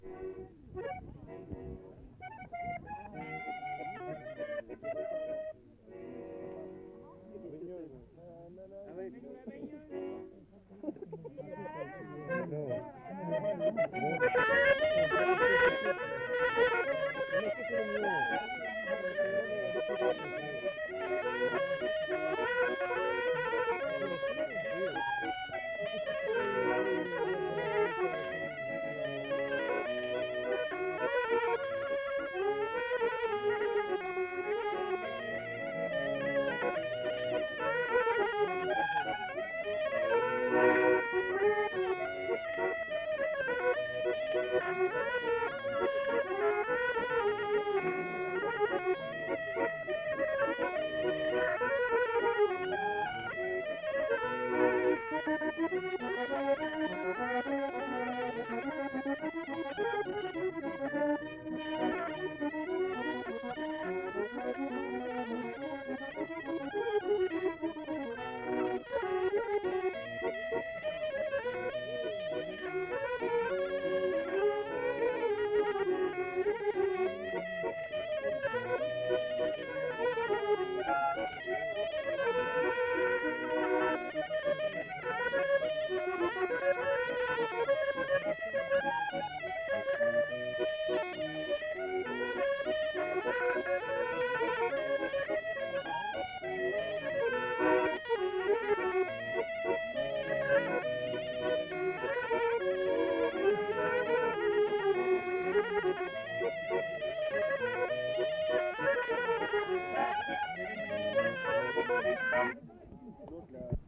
Aire culturelle : Viadène
Genre : morceau instrumental
Instrument de musique : cabrette ; accordéon chromatique
Danse : valse